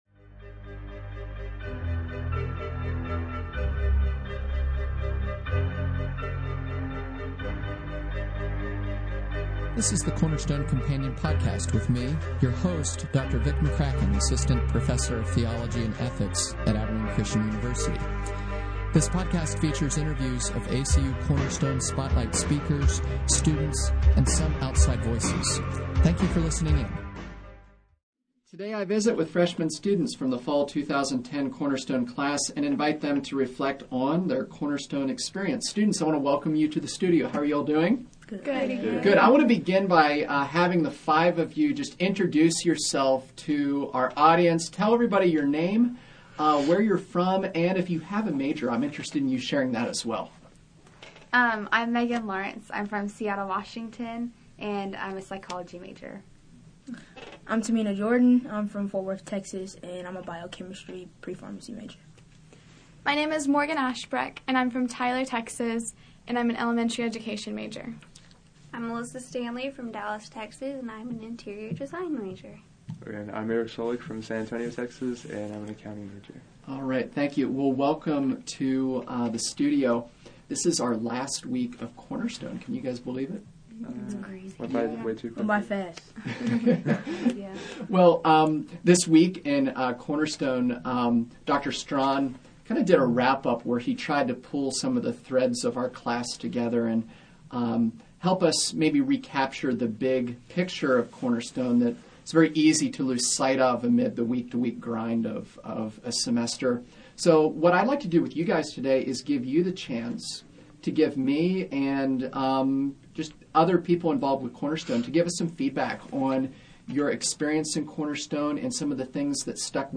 Class-Interview.R-1.mp3